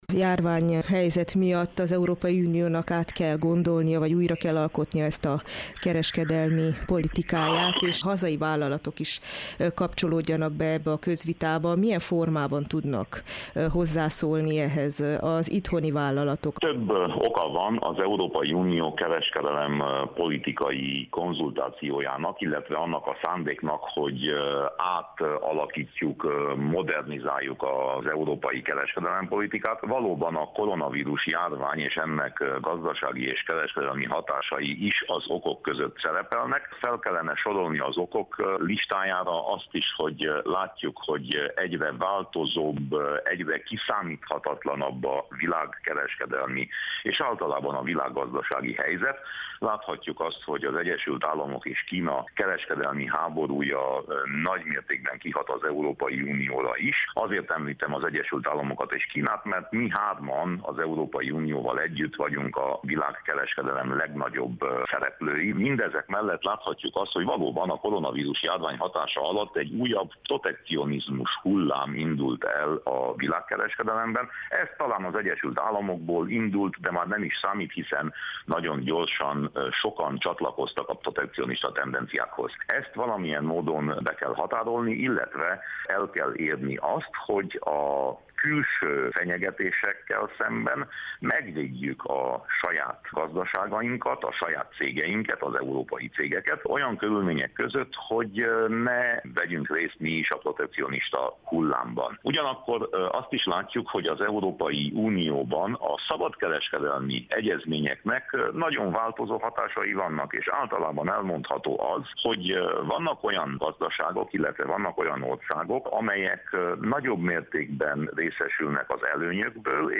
Winkler Gyula EP képviselőt kérdezte